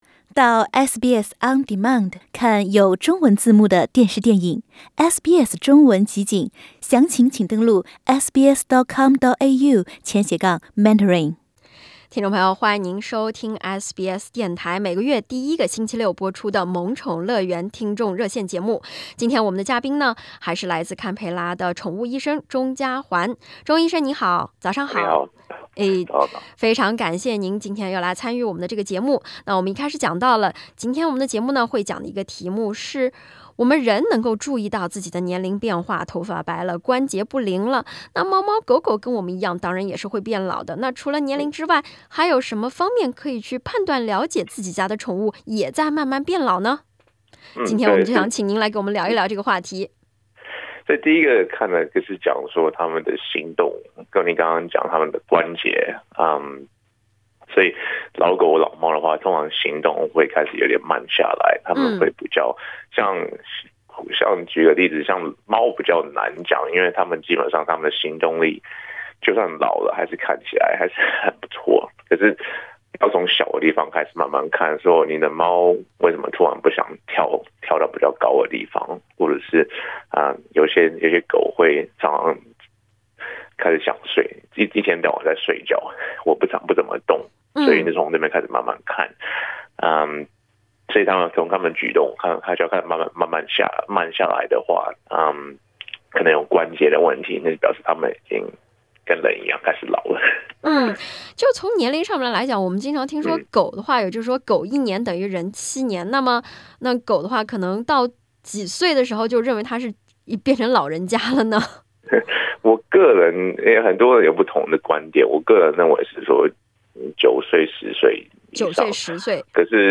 他还回答了听众提出的老猫毛发打结要如何处理，如何防治可能造成狗狗皮毛脱落的病菌，人的补品能不能喂患有关节炎的小狗吃等问题。